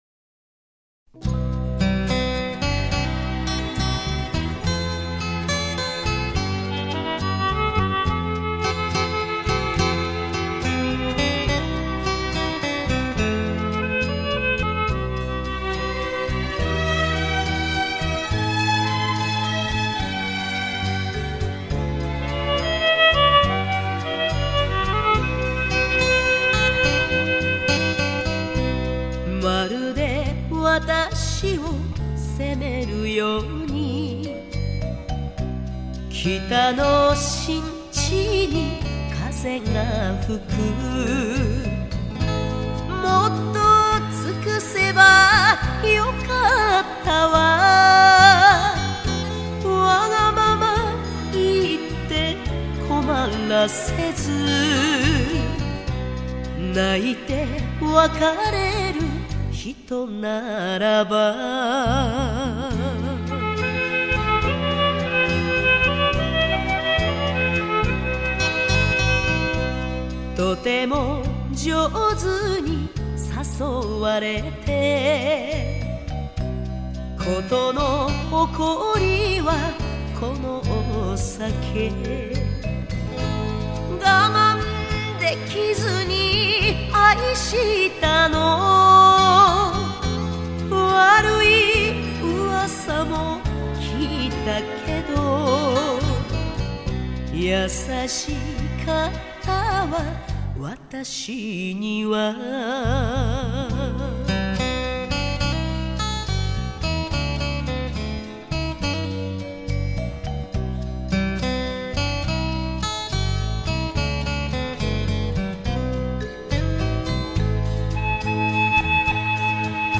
演歌歌手。